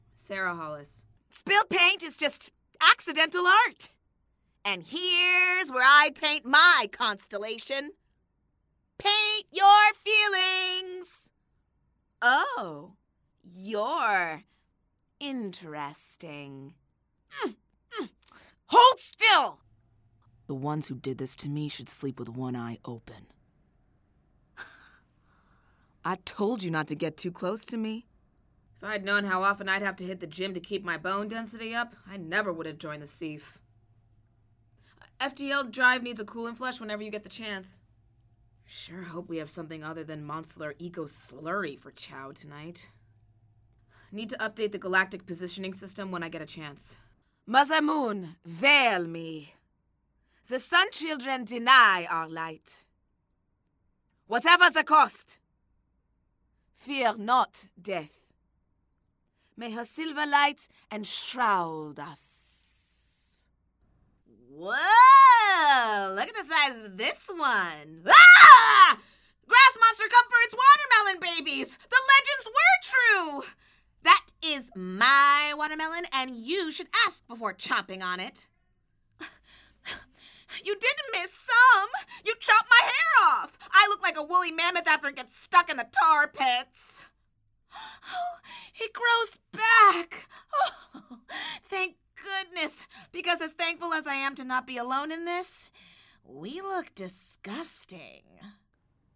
Voice Over
VO Animation Reel